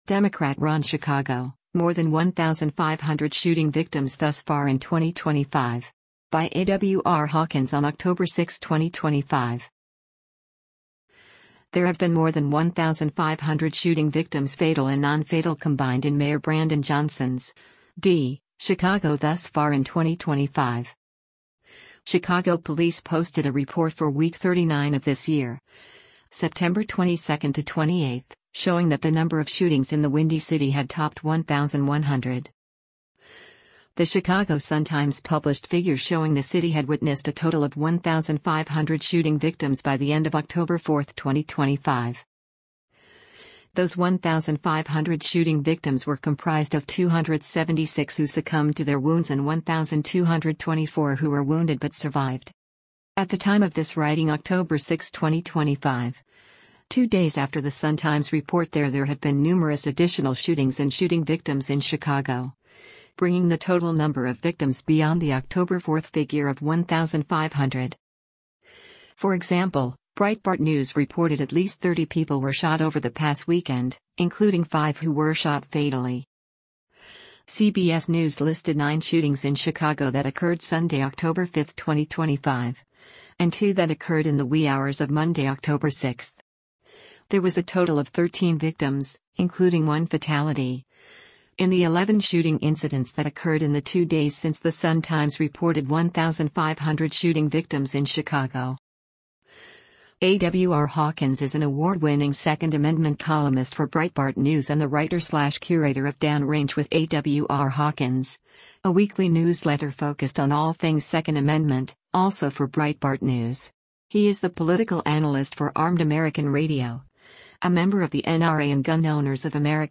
mayor of chicago speaks